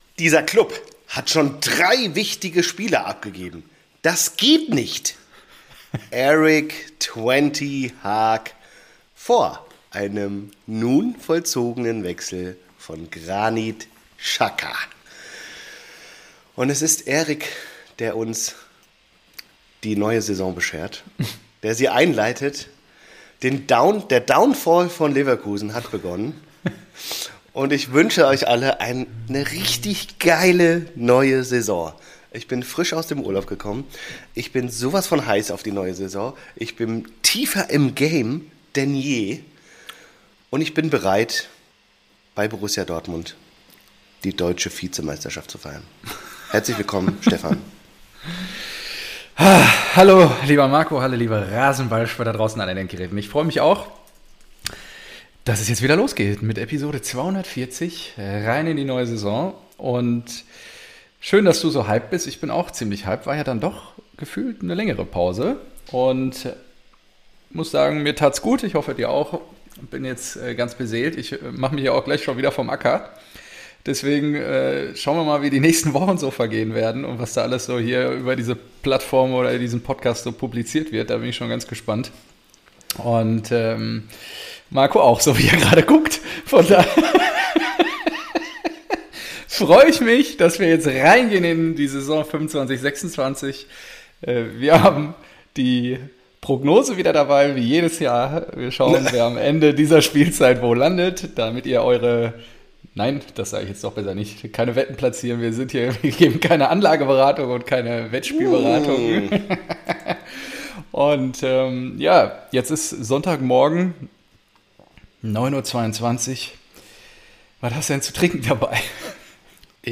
Ein BVB-Fan quatscht mit seinem SGE-Kumpel. Der Podcast für alle Vereine, die vor 2009 gegründet wurden.